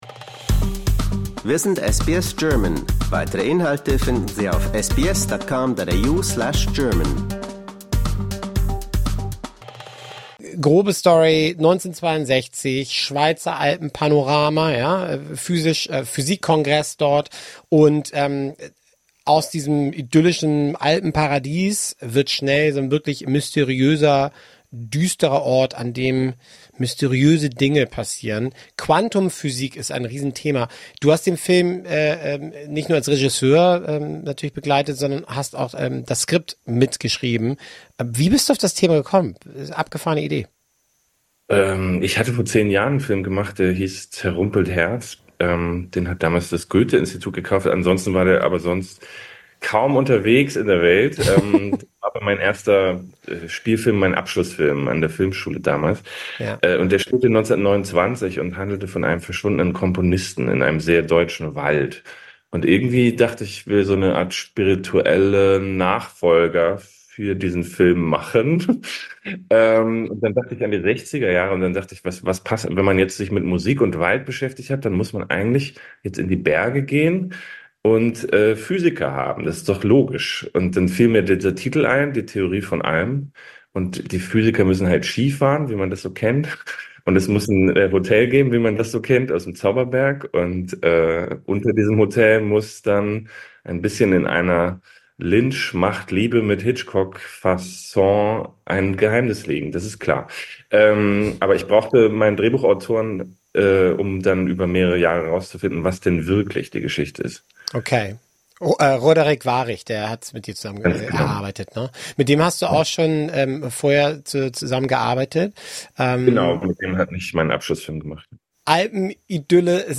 Aus Deutschland, Österreich & der Schweiz kommt der Film „Die Theorie von allem“ – ein Thriller in Schwarz weiß, um das Thema der Quantenphysik. Im Gespräch